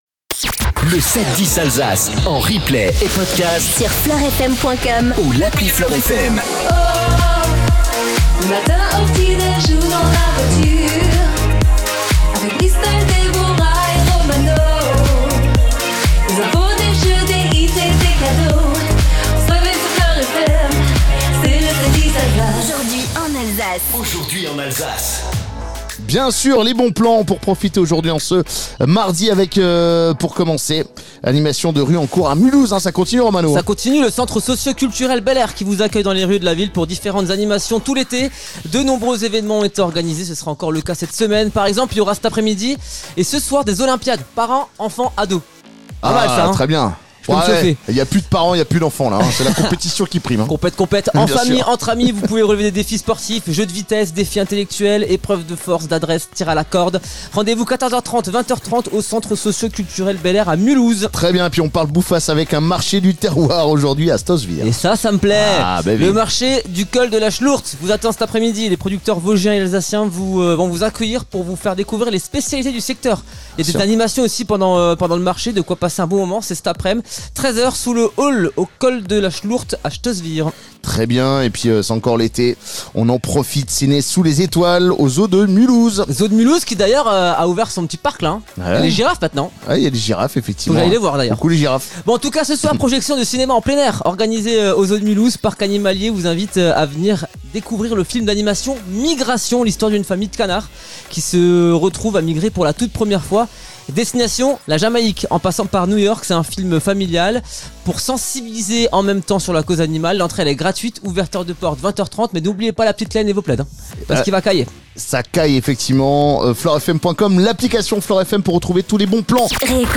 710 ALSACE FLOR FM PODCAST MORNING COLMAR CREDIT MUTUEL ENTREPRISES LK FLORFM Mardi 26 août 0:00 29 min 49 sec 26 août 2025 - 29 min 49 sec LE 7-10 DU 26 AOÜT Retrouvez les meilleurs moments du 7-10 Alsace Tour 2025, ce mardi 26 août dans les rues de Colmar.